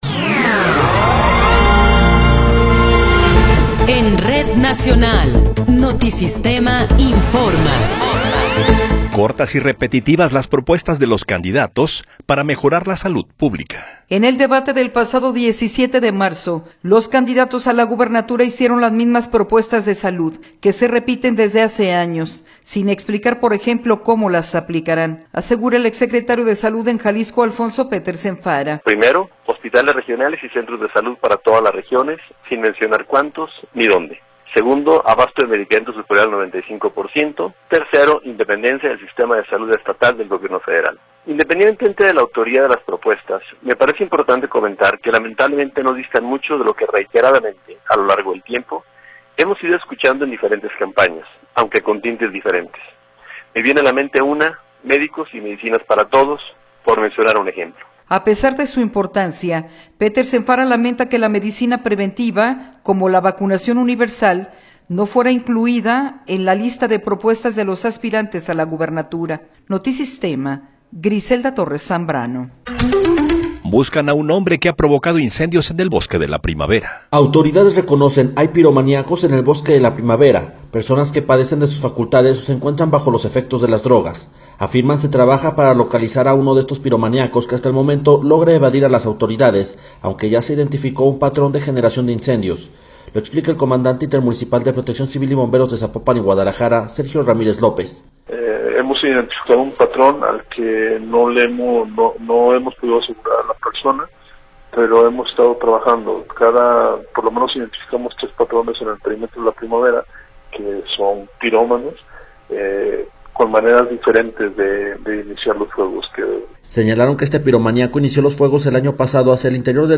Noticiero 13 hrs. – 20 de Marzo de 2024